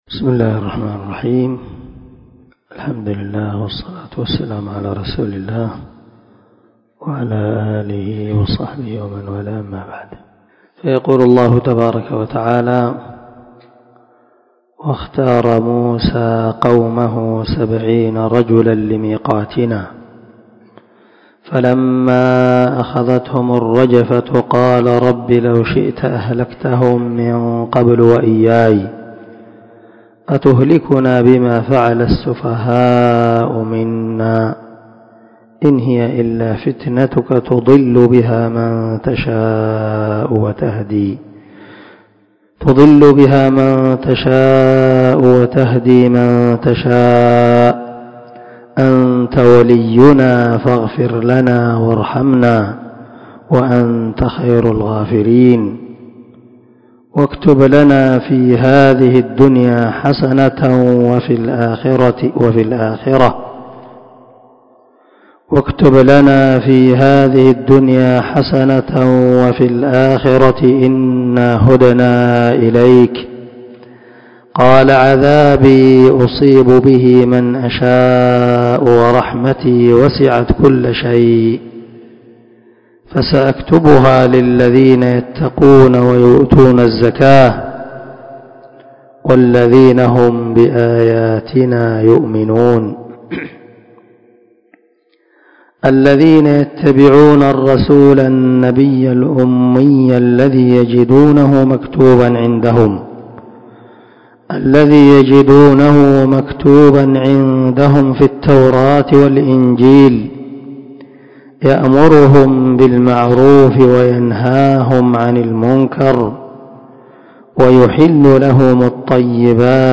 487الدرس 39 تفسير آية ( 155 – 157 ) من سورة الأعراف من تفسير القران الكريم مع قراءة لتفسير السعدي